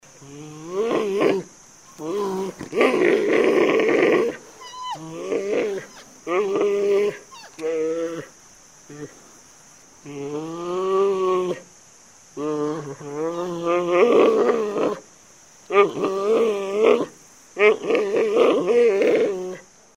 Звуки панды
Голос панды